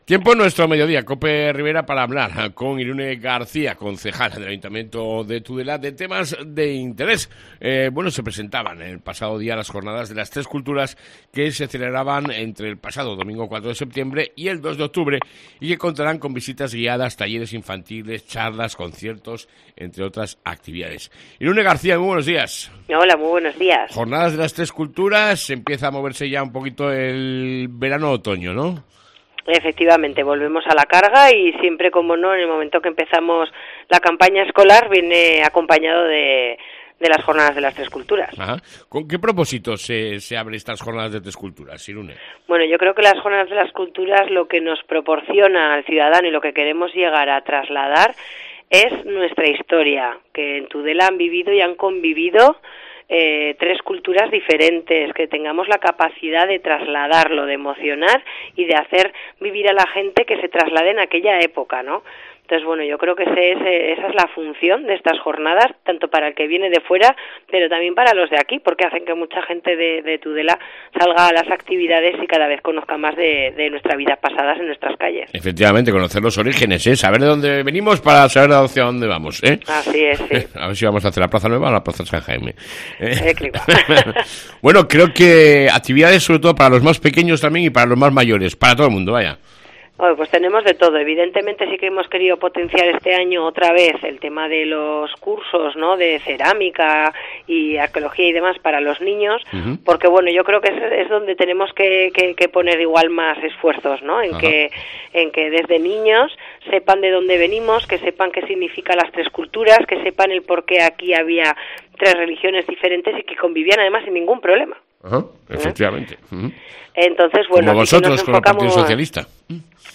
Entrevista con la concejal de Irune garcía